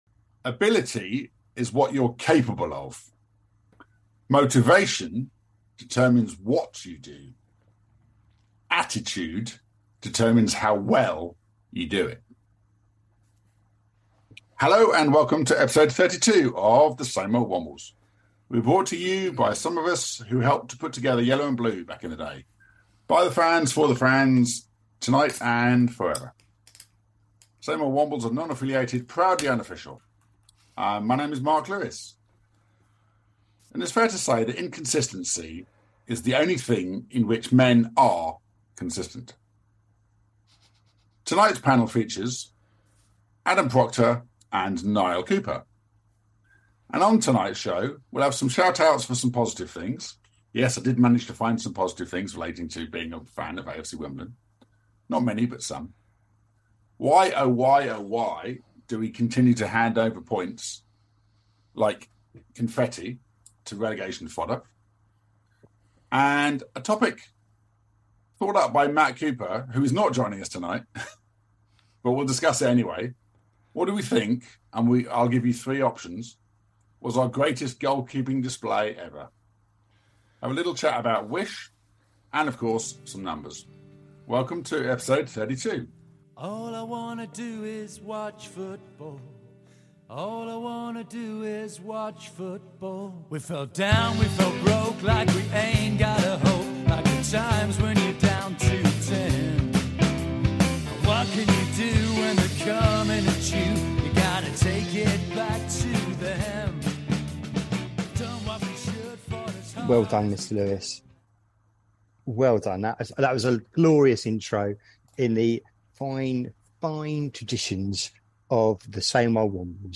Apologies for any audio quirks this week